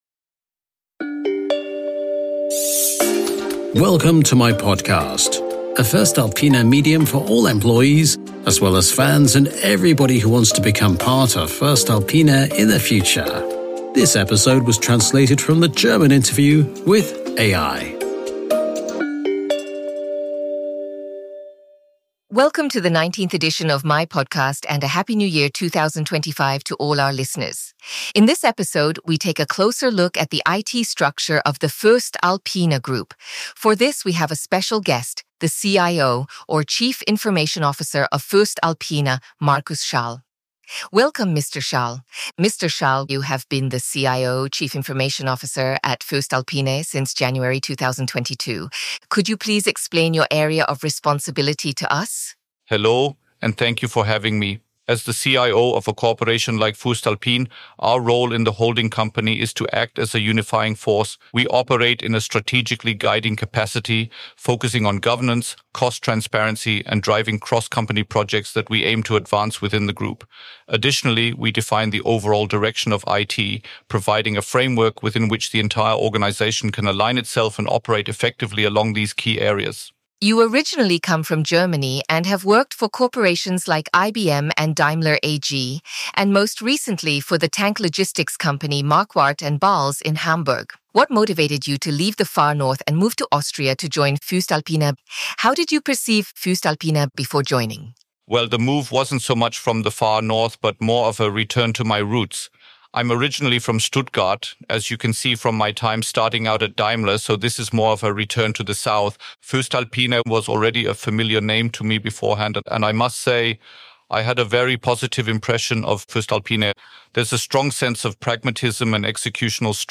This episode has been translated into English using AI from their original conversation in German and the voices were also artificially generated.